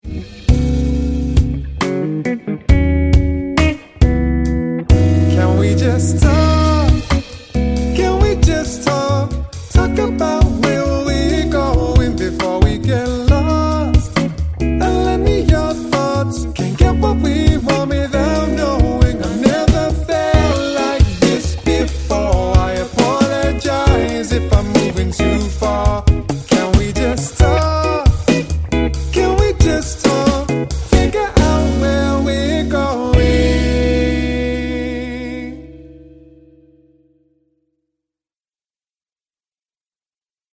rhythmic and melodic acoustic act
• Features male vocal harmonies